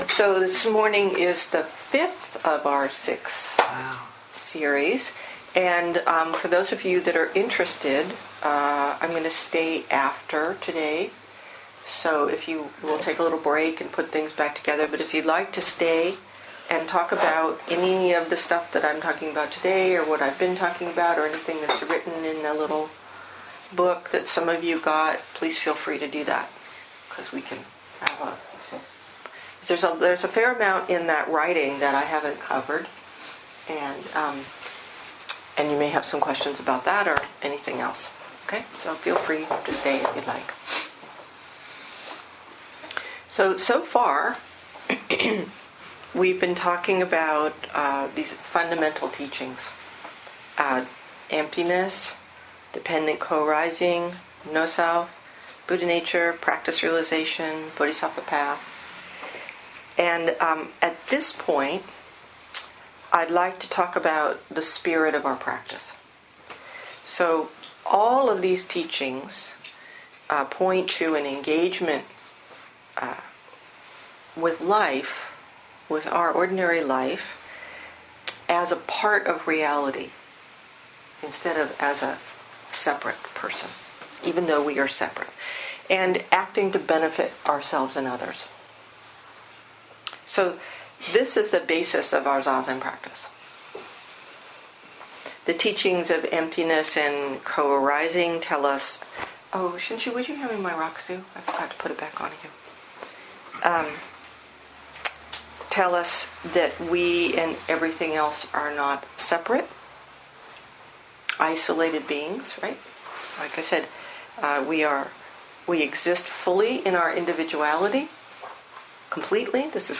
2014 in Dharma Talks